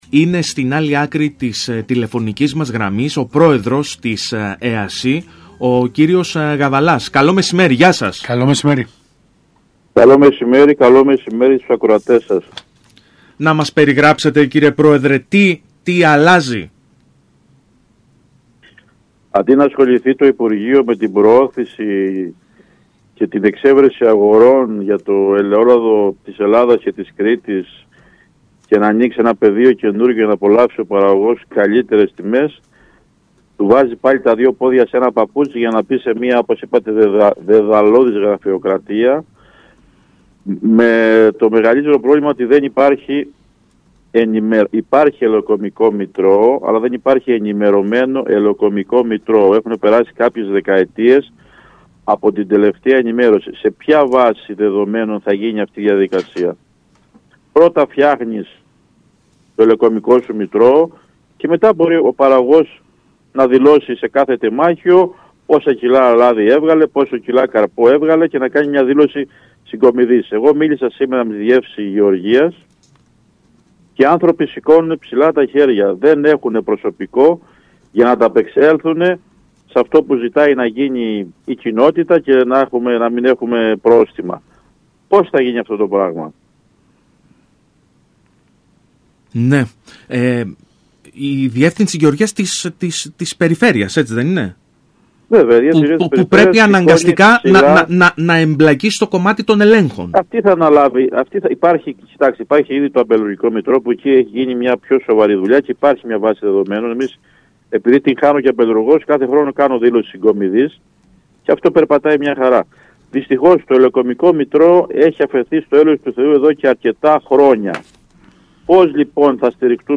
δήλωσε στο ραδιόφωνο του ΣΚΑΪ Κρήτης 92,1